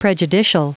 Prononciation du mot prejudicial en anglais (fichier audio)
Prononciation du mot : prejudicial